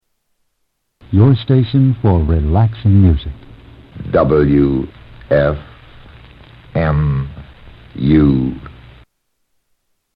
Another Station ID for WFMU
Tags: Radio Radio Stations Station I.D. Seques Show I.D